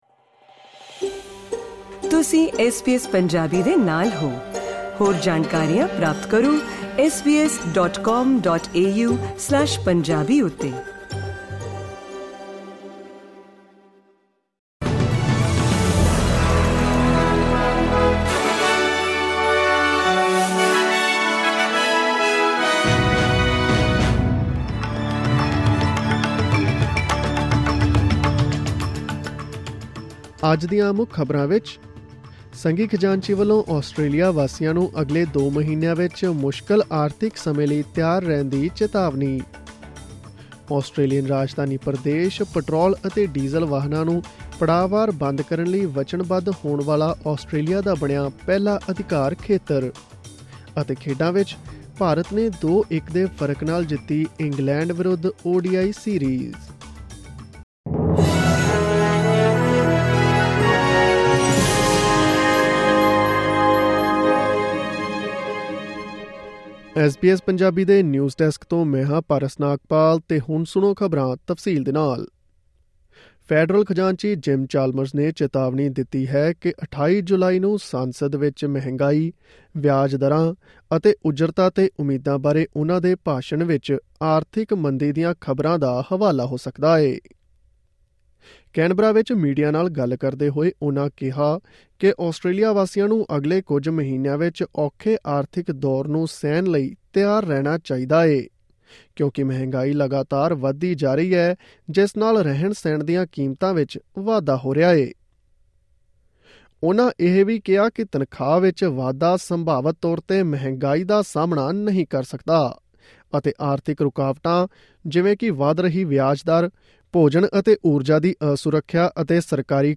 Click on the audio button to listen to the news bulletin in Punjabi.